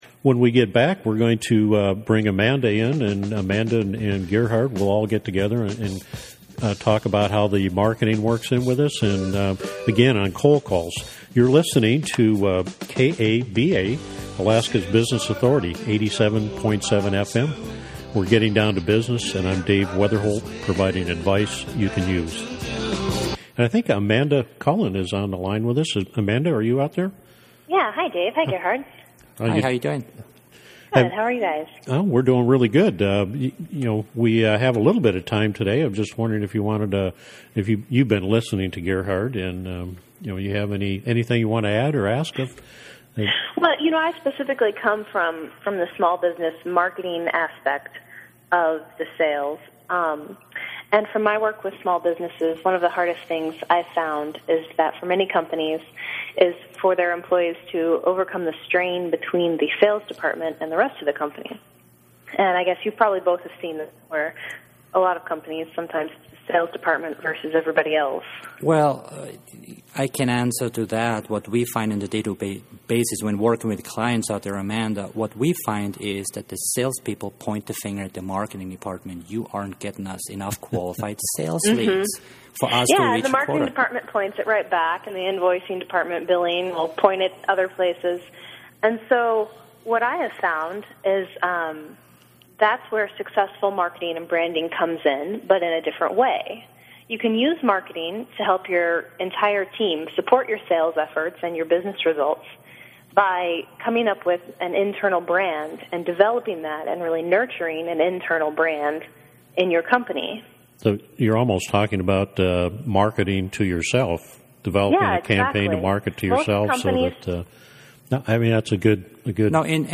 Radio Interviews